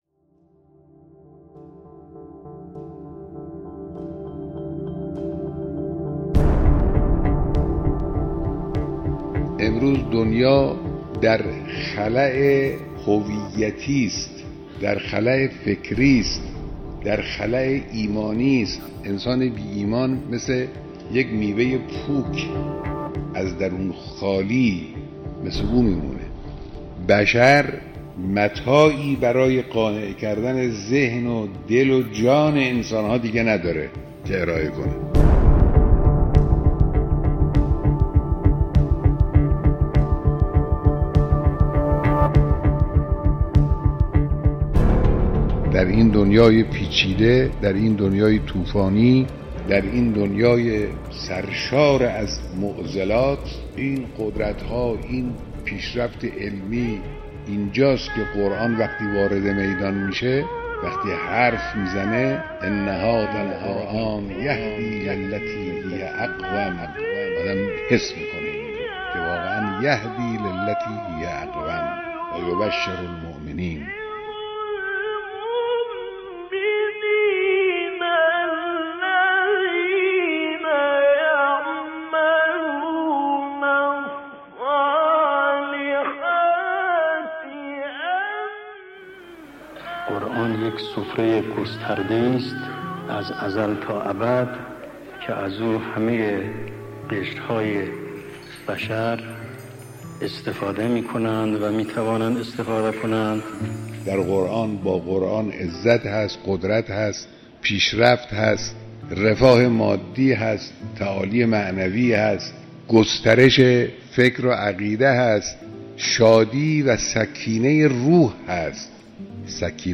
«اگر چنانچه رشحه‌ای از رشحات قرآن با بیان متناسب امروز فرستاده بشود، دلها جذب می‌شود» (رهبر معظم انقلاب)» به مناسبت حلول ماه مبارک رمضان، صوت رهبر معظم انقلاب پیرامون کتاب آسمانی قرآن را می‌شنوید.